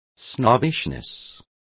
Complete with pronunciation of the translation of snobbishness.